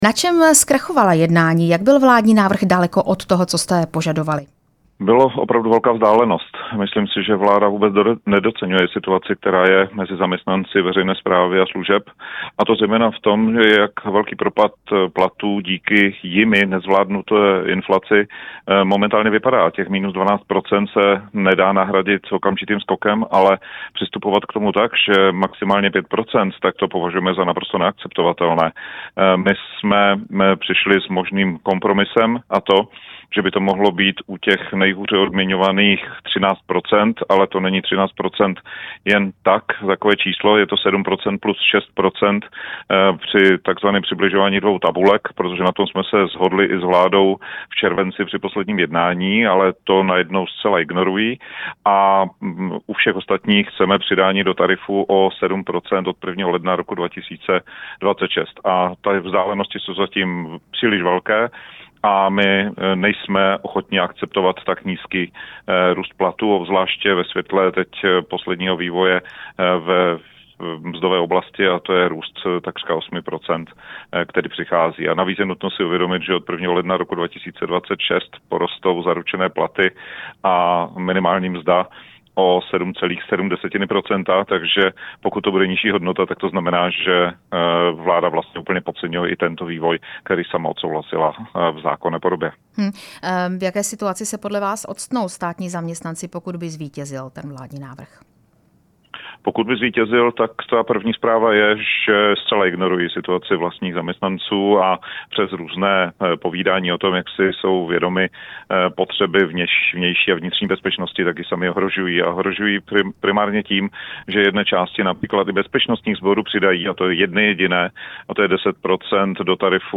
Odboráři ale žádají víc. Do vysílání Radia Prostor jsme si pozvali Josefa Středulu, předsedu Českomoravské konfederace odborových svazů.
Rozhovor s odborovým předákem Josefem Středulou